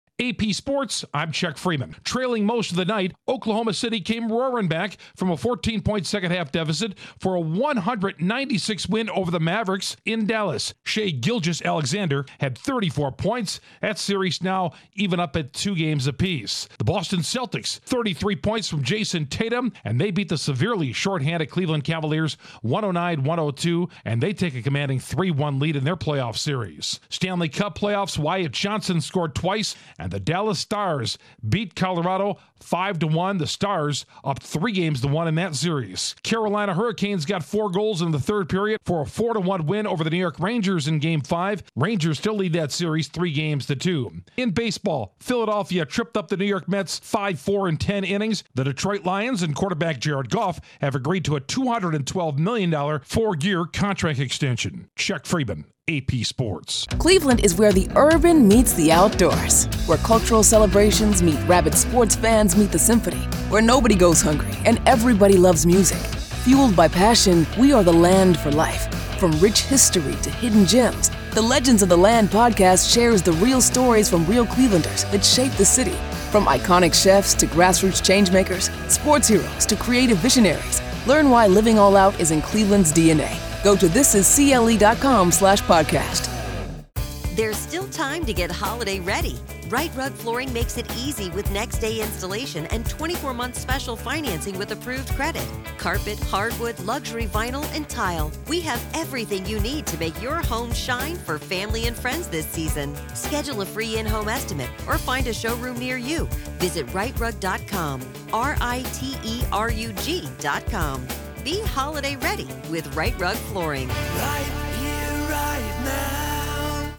The Celtics and Thunder both score road playoff wins, the Stars are on the verge of eliminating the Avs in Stanley Cup playoffs, the Braves blank the Cubs, and NFL quarterback Jared Goff gets an extension with the Lions. Correspondent